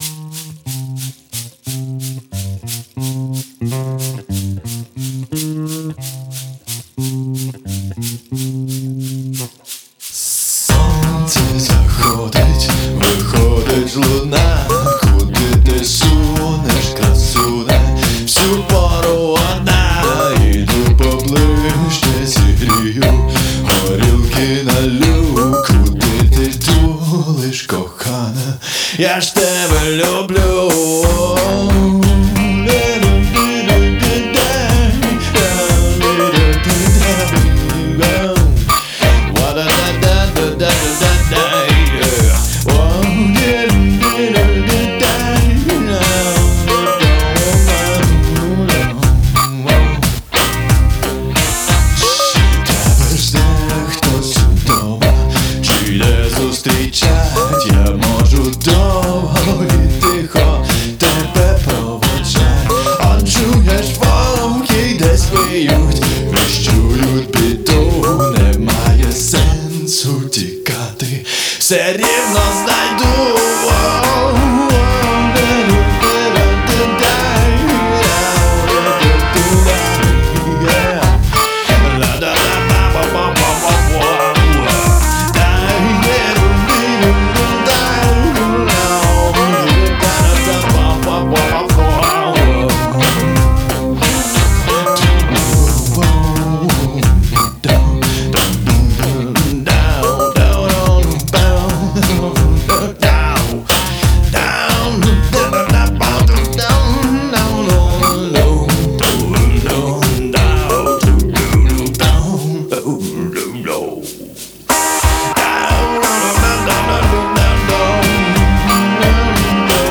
категорії: музика Баришня та хуліган теґи: blues , glitch , рок Файл: VOVKY-1.mp3 This text will be replaced Пісня, яку я написав своїй дружині на геловін.